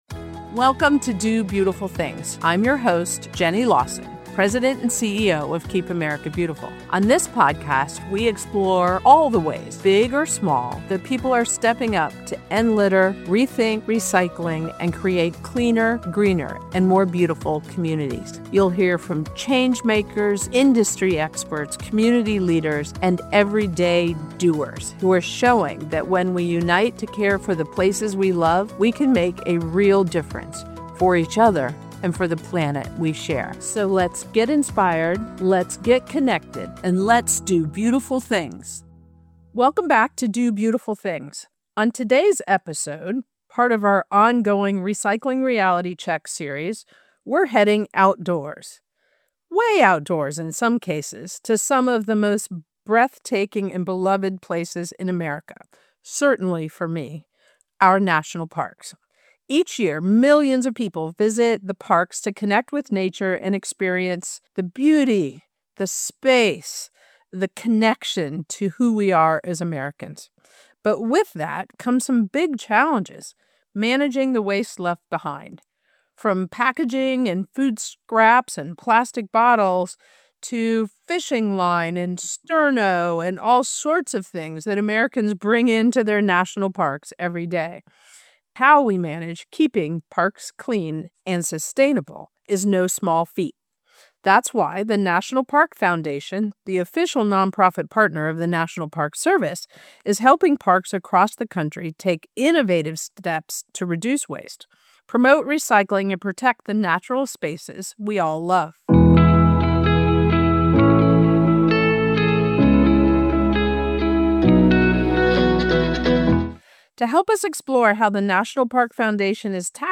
In this podcast we will discuss ending litter, the truth behind recycling, and enhancing communities to create a more sustainable and beautiful future. Our engaging discussions are not just interviews; they are insightful conversations that illuminate the path to a greener tomorrow.